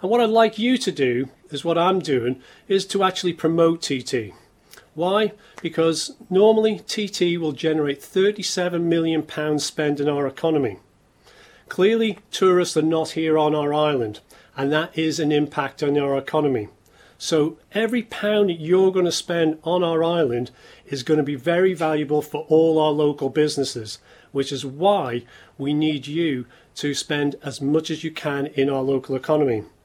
Enterprise Minister Laurence Skelly made the plea at today's government media conference.